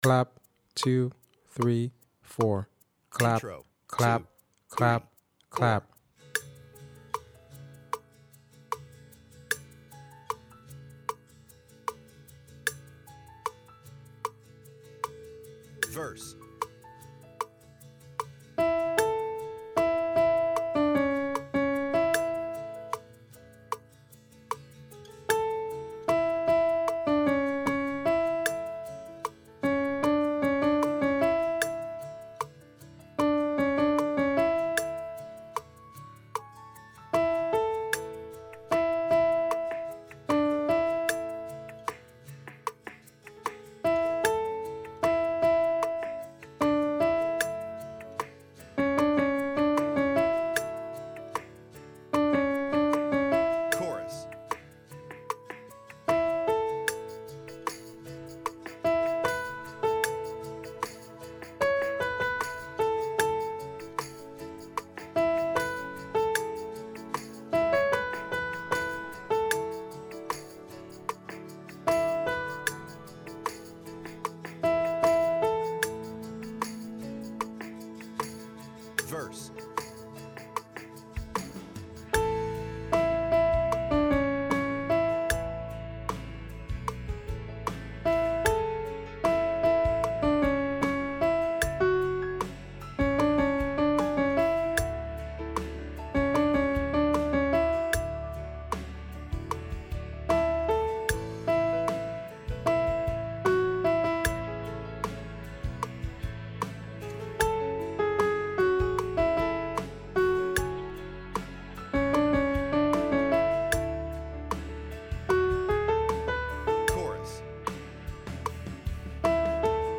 Backing Track -